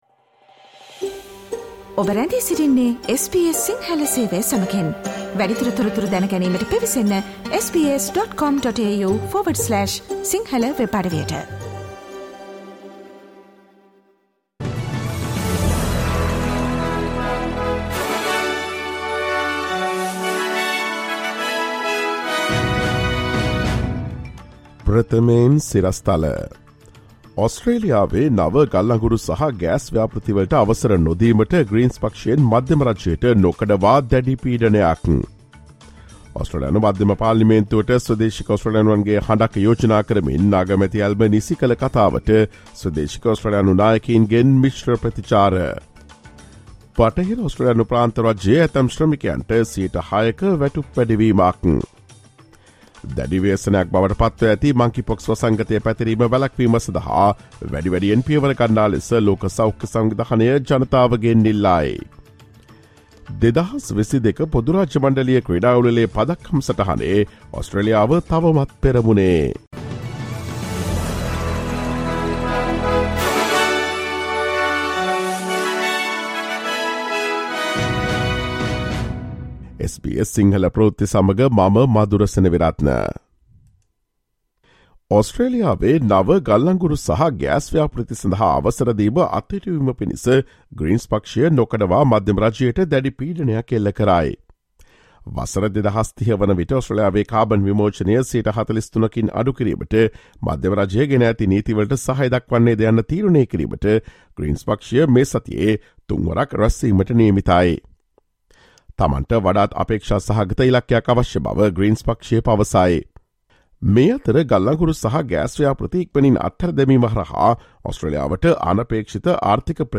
ඔස්ට්‍රේලියාවේ නවතම පුවත් මෙන්ම විදෙස් පුවත් සහ ක්‍රීඩා පුවත් රැගත් SBS සිංහල සේවයේ 2022 අගෝස්තු 01 වන දා සඳුදා වැඩසටහනේ ප්‍රවෘත්ති ප්‍රකාශයට සවන් දීමට ඉහත ඡායාරූපය මත ඇති speaker සලකුණ මත click කරන්න.